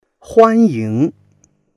huan1ying2.mp3